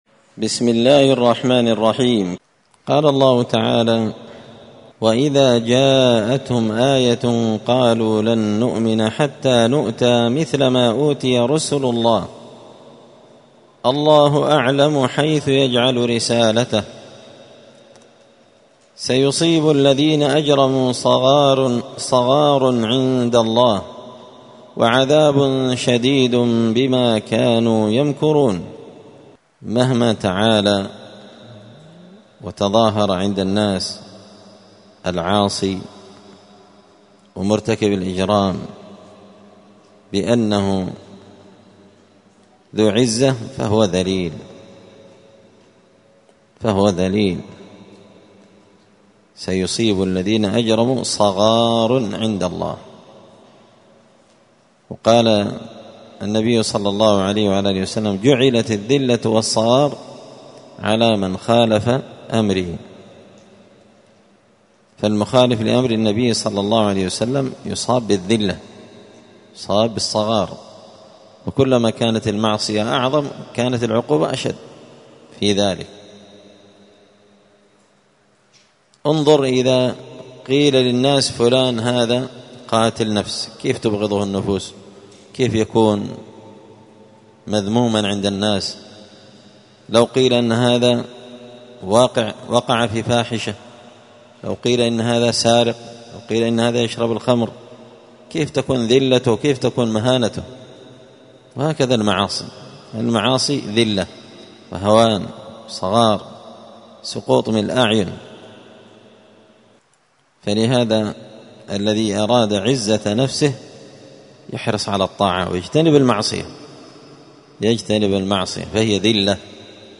مختصر تفسير الإمام البغوي رحمه الله الدرس 352